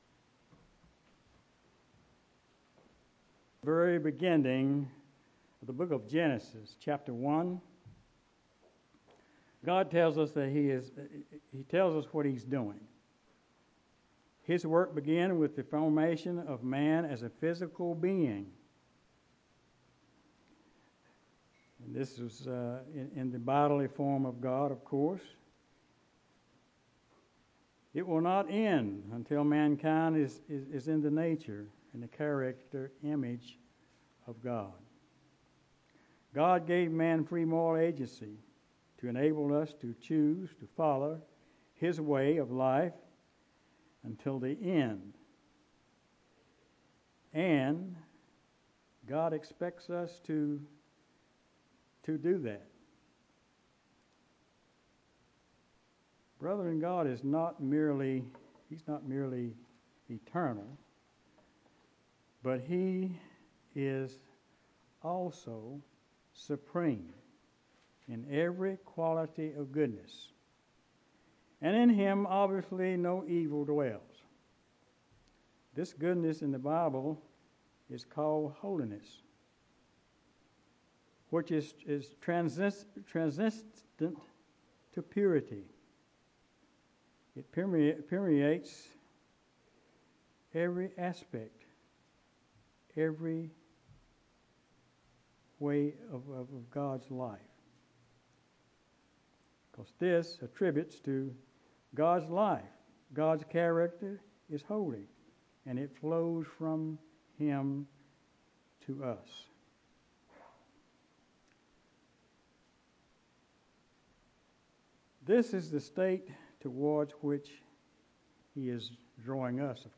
UCG Sermon Studying the bible?
Given in Greensboro, NC